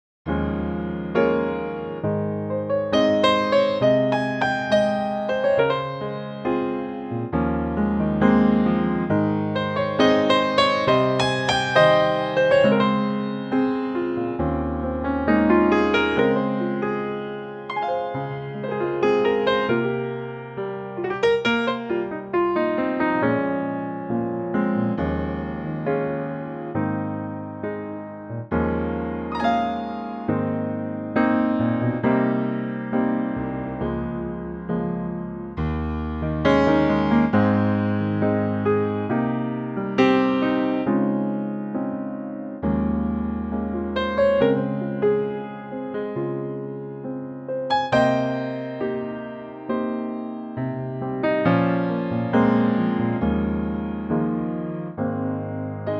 Unique Backing Tracks
key - Ab - vocal range - F to Ab (optional Bb)
A gorgeous piano only arrangement